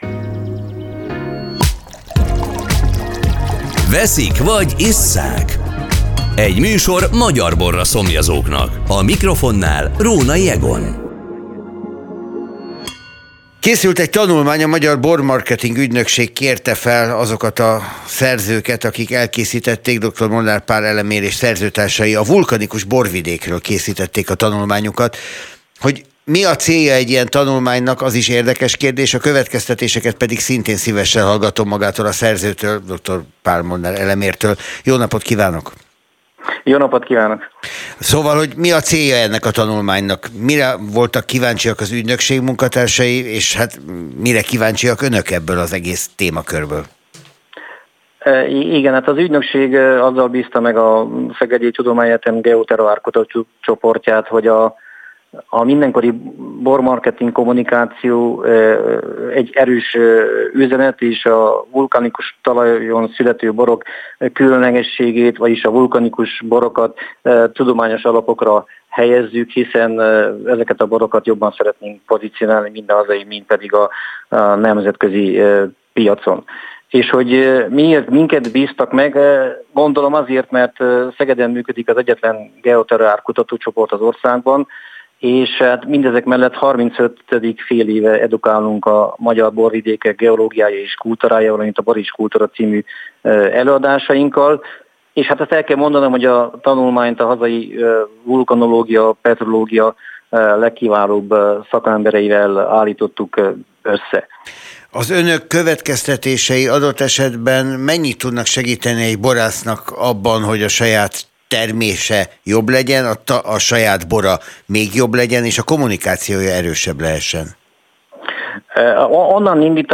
Spirit FM interjú
spiritfm-interju